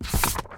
x_enchanting_scroll.13.ogg